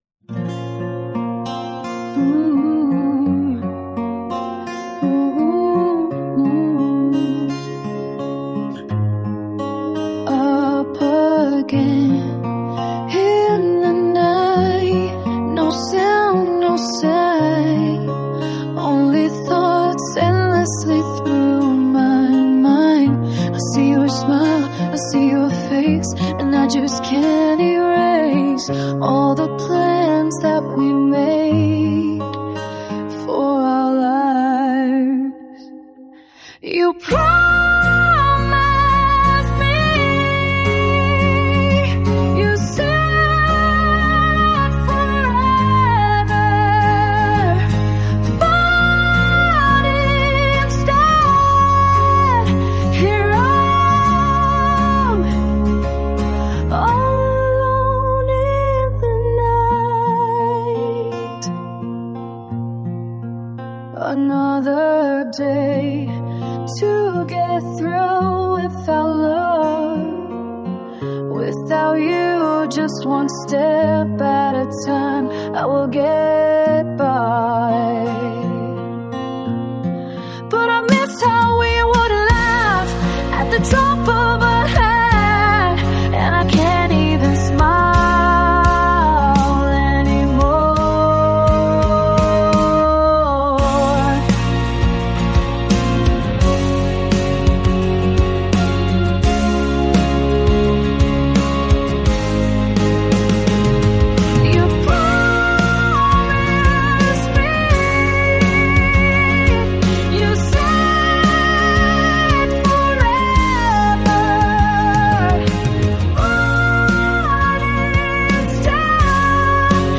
You Promised Me: with full song and band audio
With some electric guitars this could rock but it stands on its own as a powerful ballad.
It's still all my lyrics, just added music and vocal.